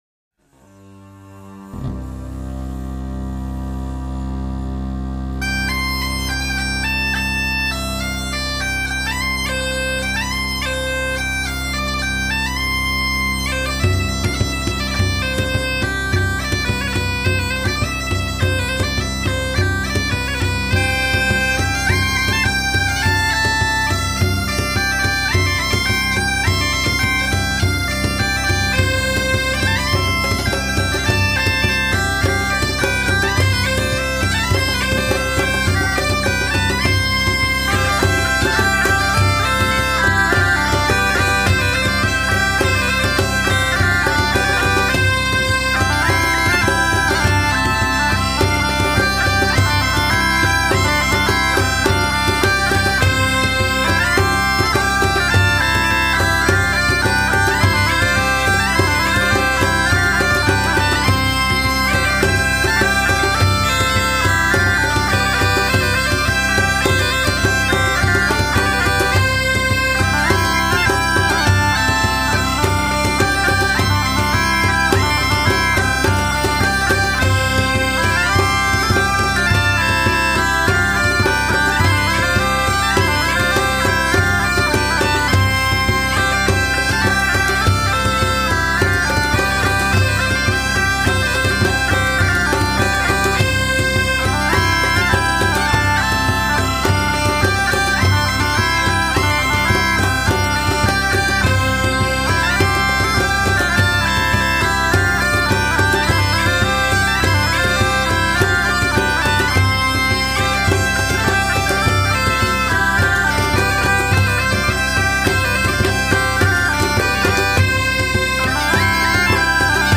Musiques d'Alsace et d'Ailleurs
branle